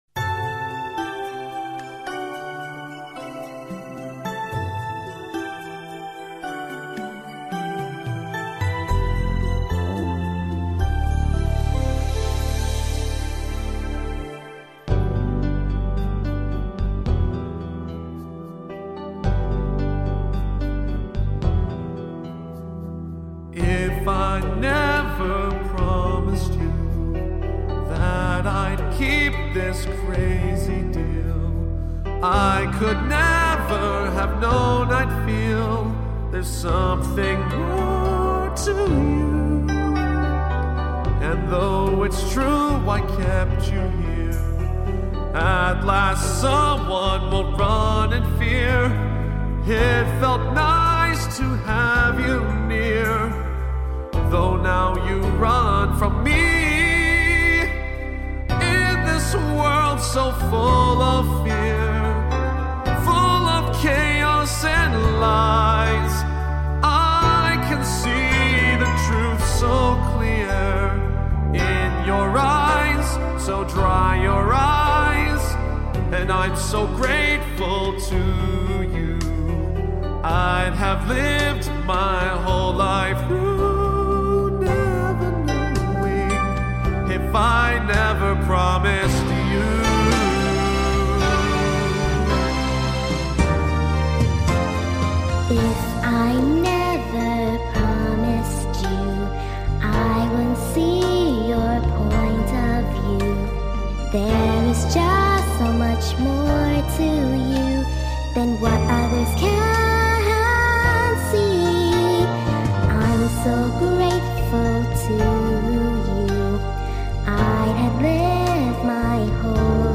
Fan Song
My singing duo with the freaking amazing voice (Thank you so much for agreeing to lend me your amazing voice.